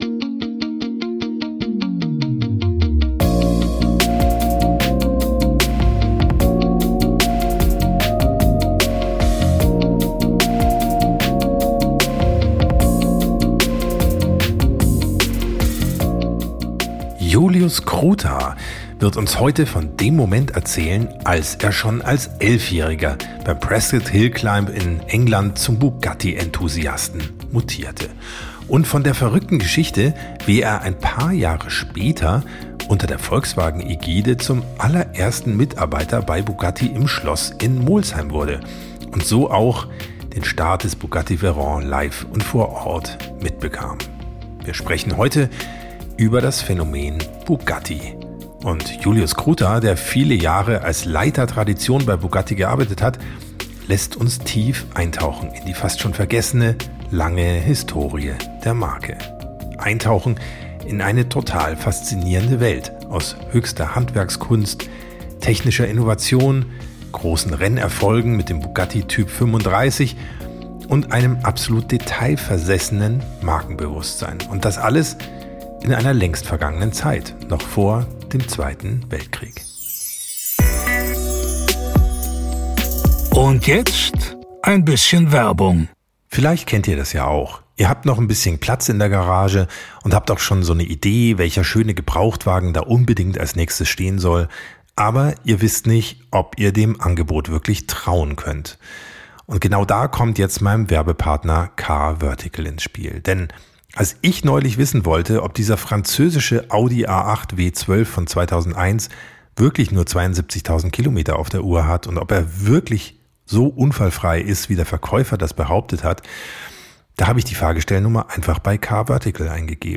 Und deshalb bin ich sehr froh, dass ich ihn im Nationalen Automuseum - The Loh Collection treffen konnte, wo neben vielen anderen großartigen Autos auch ein Bugatti Type 35 steht.